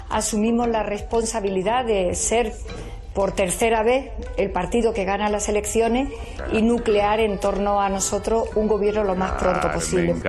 Declaraciones de Carmen Calvo